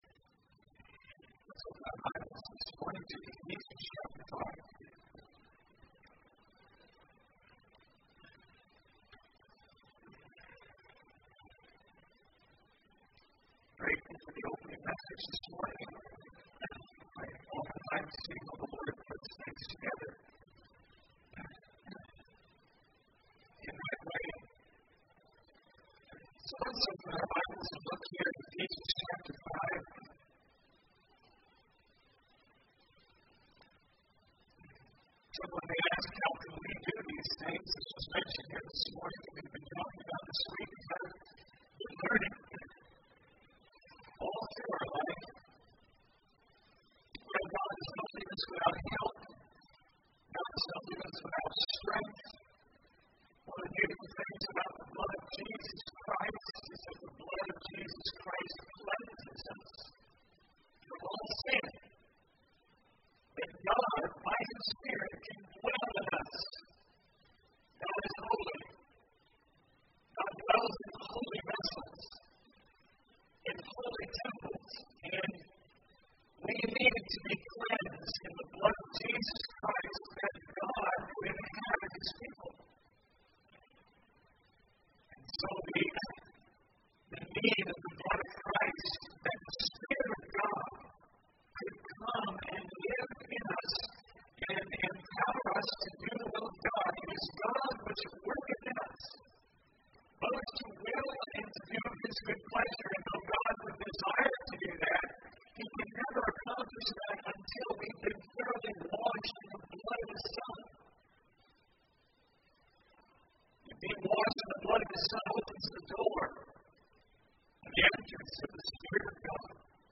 Passage: Ephesians 5:15-21 Service Type: Sunday Morning